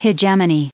971_hegemony.ogg